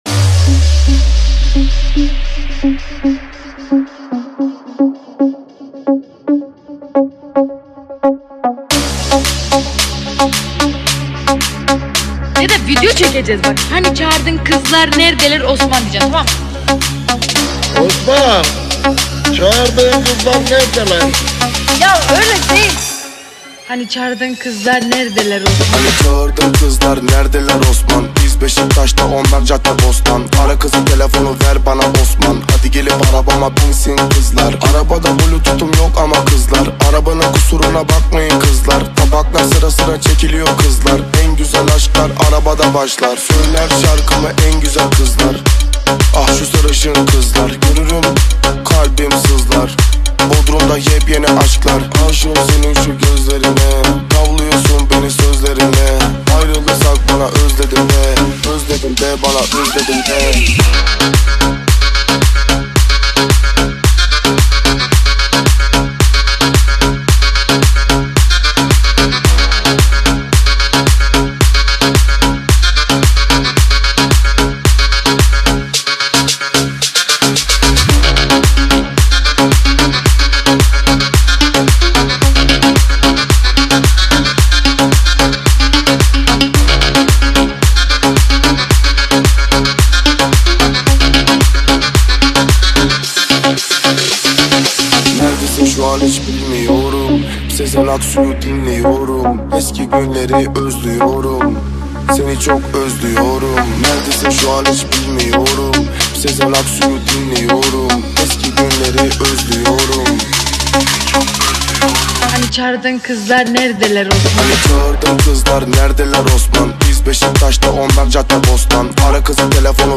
ژانر: پاپ و رپ
ریمیکس رپی ترکیه ای جدید